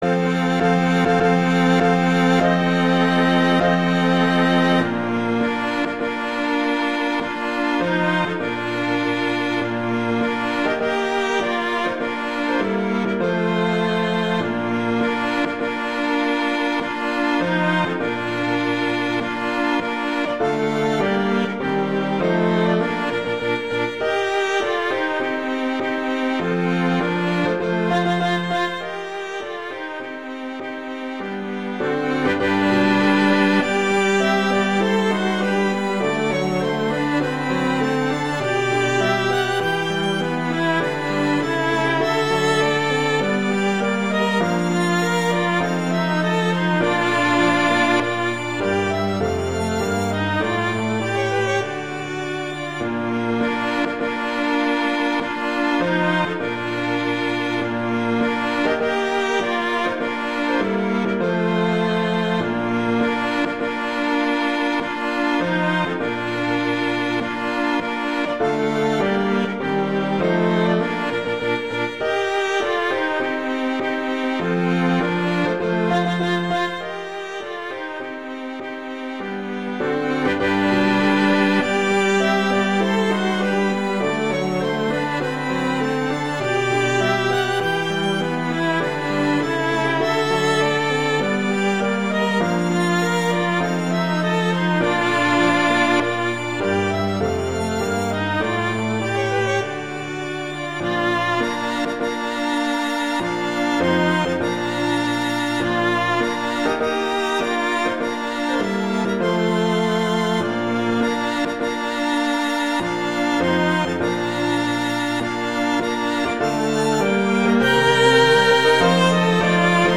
Instrumentation: piano quintet
arrangements for piano quintet
wedding, traditional, classical, festival, love, french